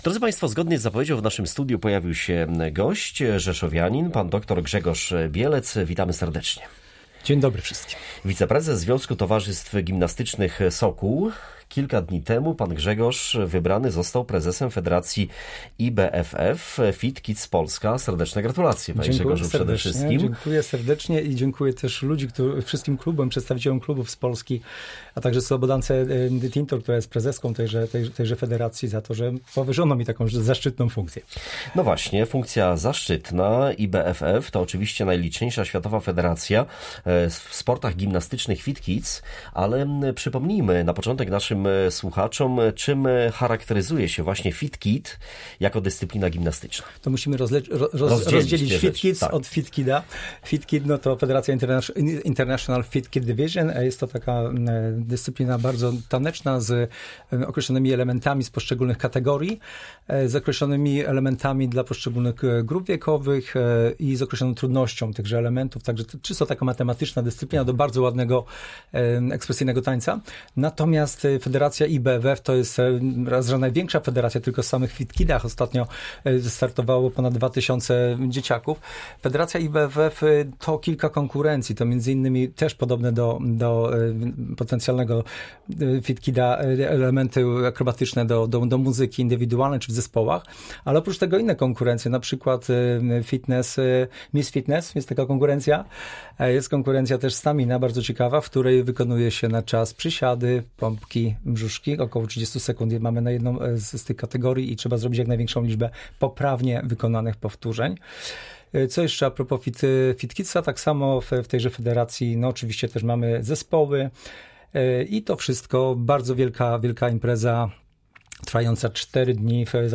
rozmawiał o tej dyscyplinie ze swoim gościem